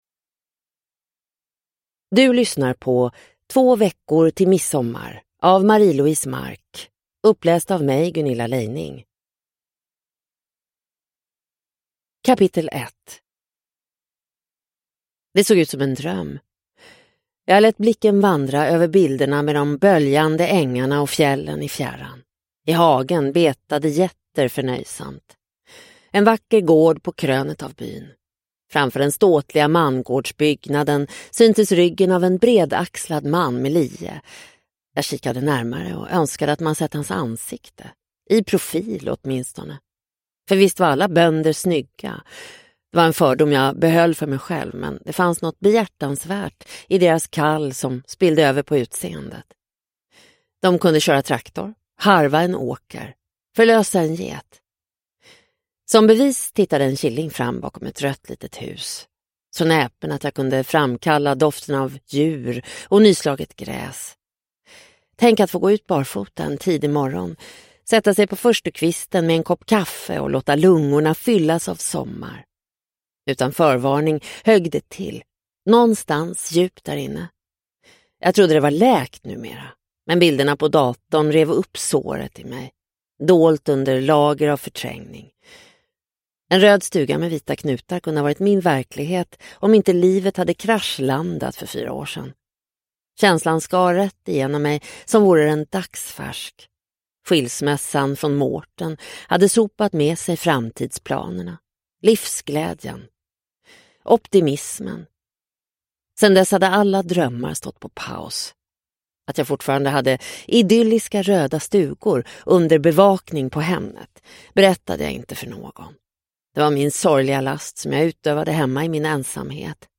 Två veckor till midsommar – Ljudbok – Laddas ner